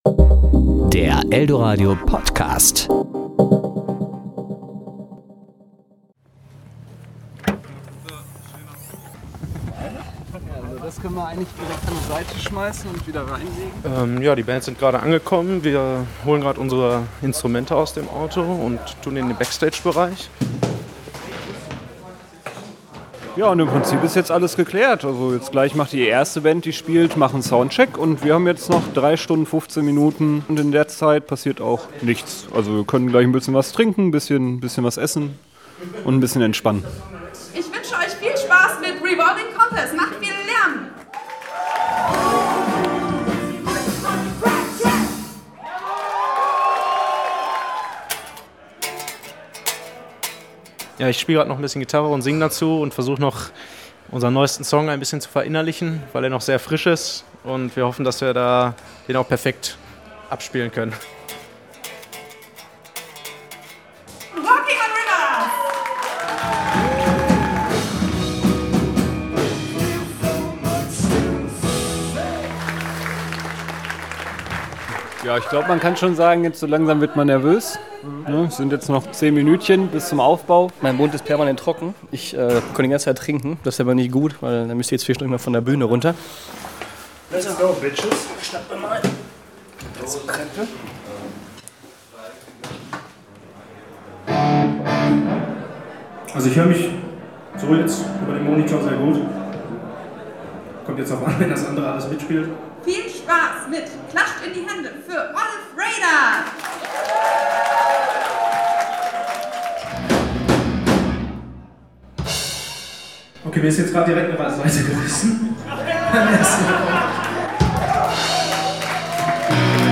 Newcomer-Bands haben es oft nicht ganz einfach: Beim Campus RuhrComer Festival bekommen sie aber eine Bühne und spielen vor großem Publikum. Wir haben Oliv Raider beim Finale begleitet. Sie hatten sich im Frühjahr in Dortmund beim Westparkfest dafür qualifiziert.